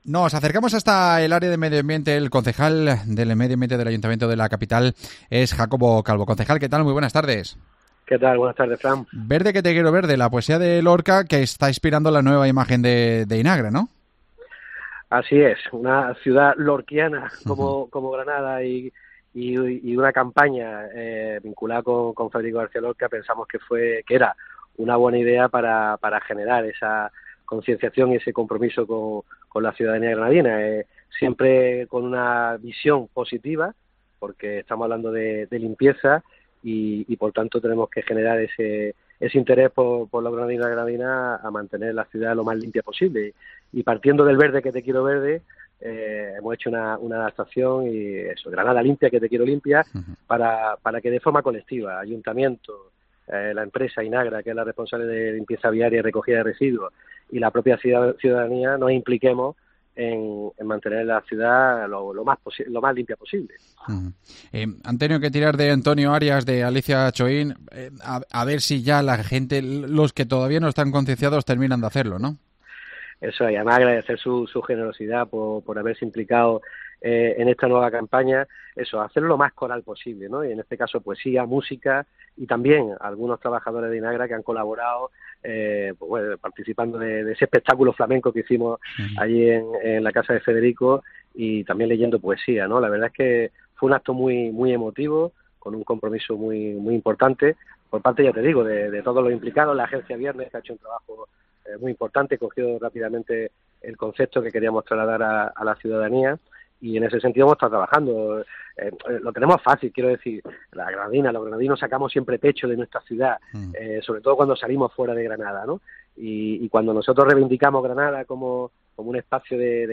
AUDIO: Hablamos con el concejal de medio ambiente del Ayuntamiento de Granada, Jacobo Calvo